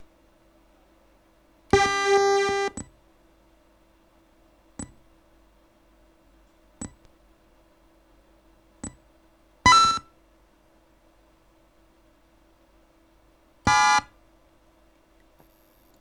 Звуки во время работы
Мелодии (воспроизводится только с отключенными внешними кнопками)